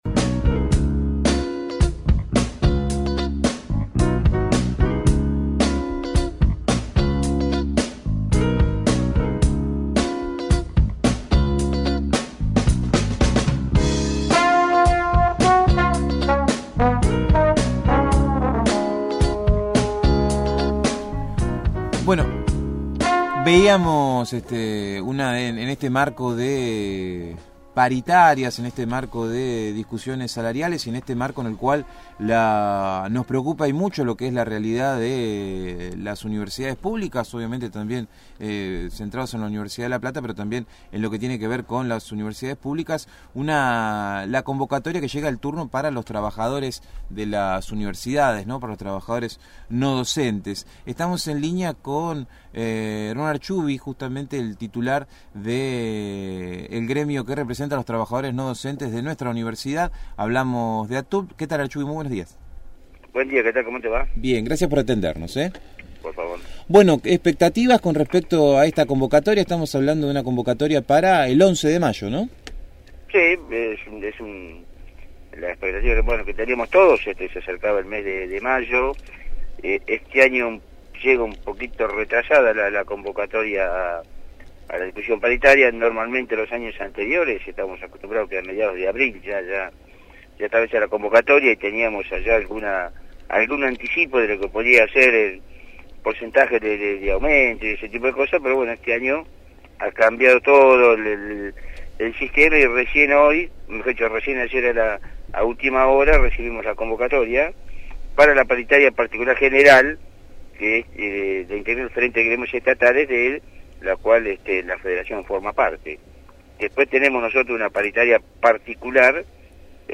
dialogó con el equipo de «El Hormiguero» sobre la convocatoria que recibió el gremio no docente por parte del gobierno para sentarse a negociar salarios el día 11 de mayo.